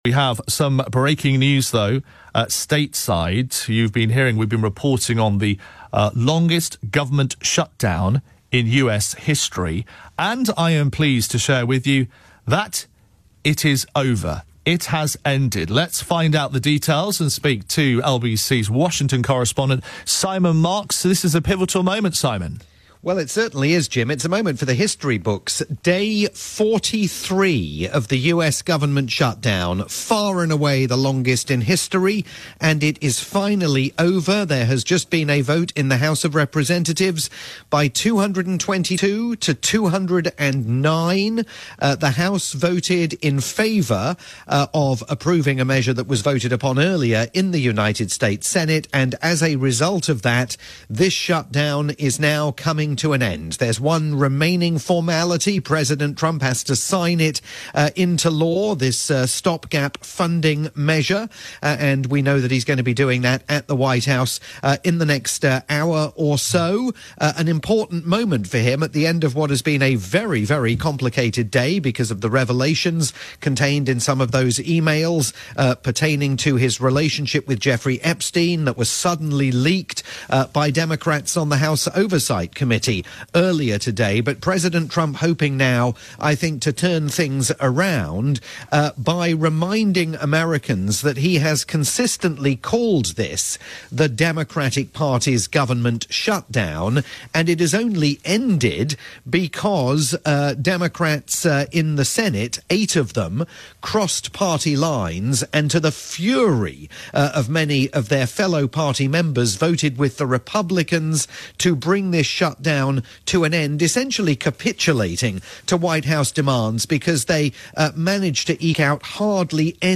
breaking news report for the overnight programme on the UK's LBC